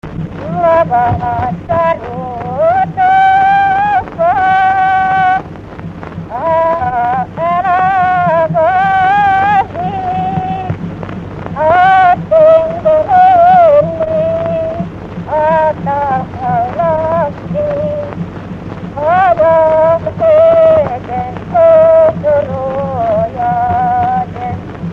Felföld - Nyitra vm. - Pográny
Stílus: 5. Rákóczi dallamkör és fríg környezete
Kadencia: 5 (5) 1 1